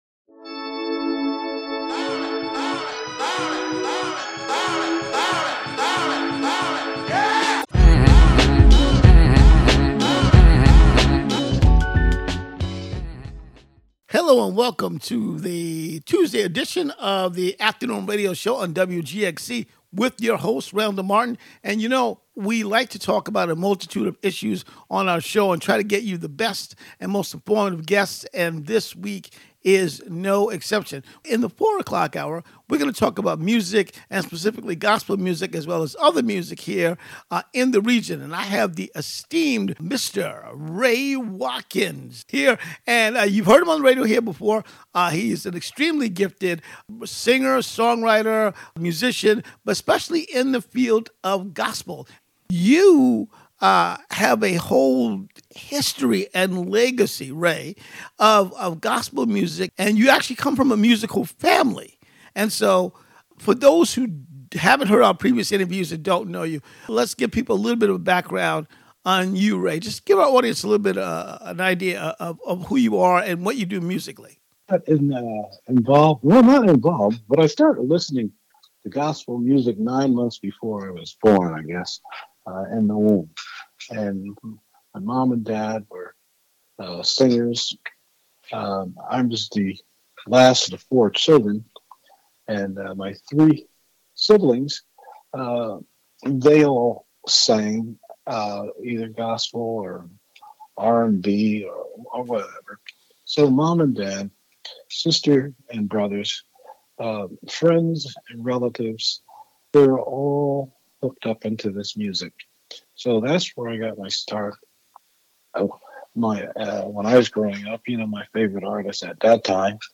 Tune in “WGXC Afternoon Show” for local news; interviews with community leaders and personalities; reports on cultural issues; a rundown of public meetings, local, and regional events; with weather updates and more about and for the community, made mostly through volunteers in the community through WGXC. The show begins each day with local headlines, weather, and previews of community events.